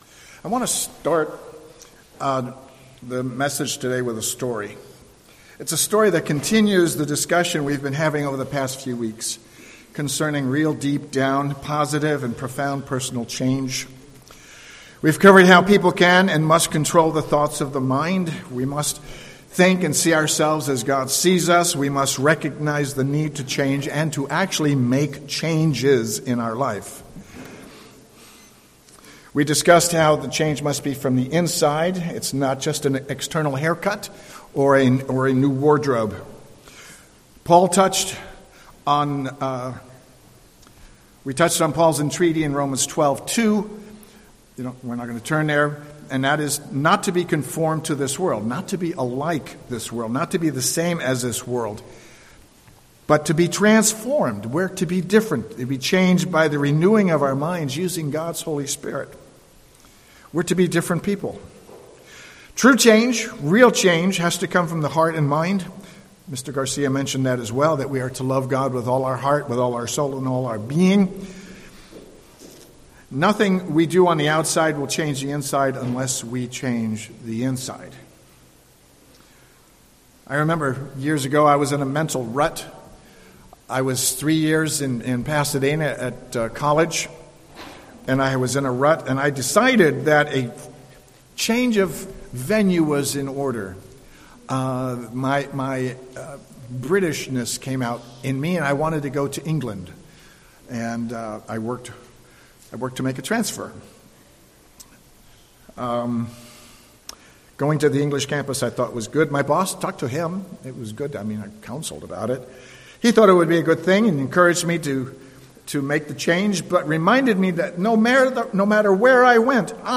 Sermon
Given in San Jose, CA